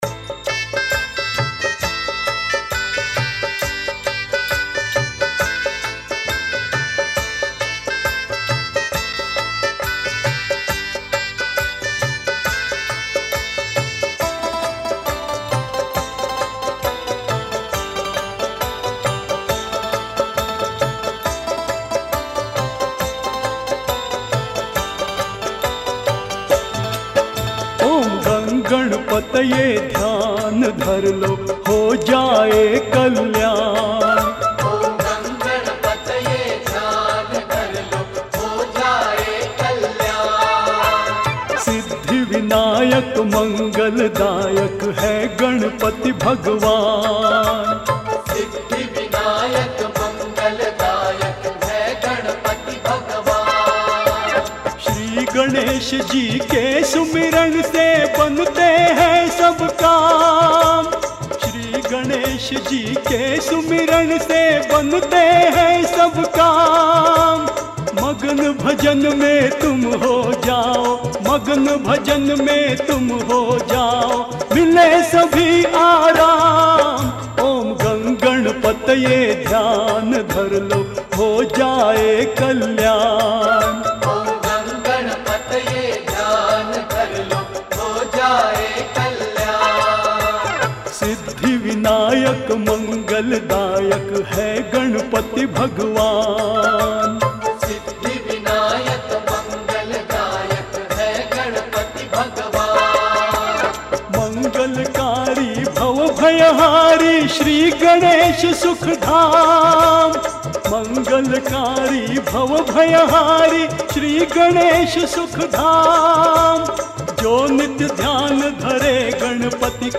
Devotional Song